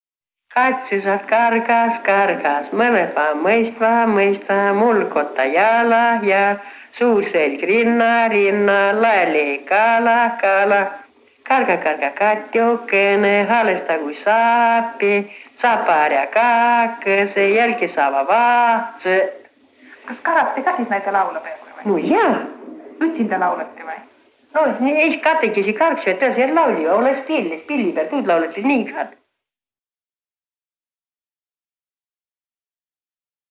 Kargusõlaul “Kats sõsart kargas”